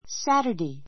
Saturday 小 A1 sǽtə r dei サ タデイ 名詞 複 Saturdays sǽtə r deiz サ タデイ ズ 土曜日 ⦣ 週の第7日.